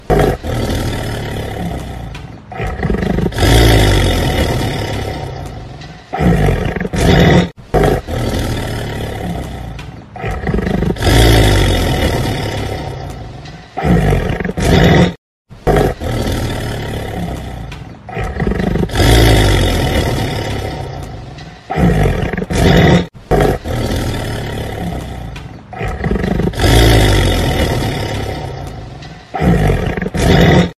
Suara Macan Kumbang
Kategori: Suara binatang liar
Dengan suara yang menggelegar dan memukau, suara ini sangat cocok untuk proyek film, video game, atau bahkan nada notifikasi yang berani dan berbeda.
suara-macan-kumbang-id-www_tiengdong_com.mp3